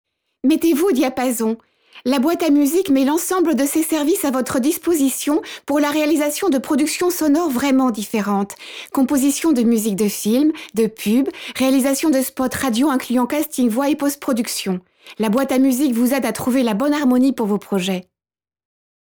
Voix Pub: Romantique